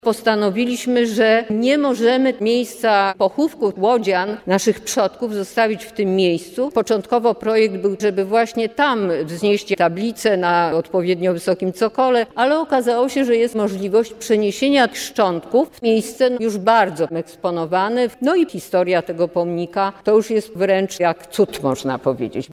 W czasie gali podsumowującej tegoroczną kwestę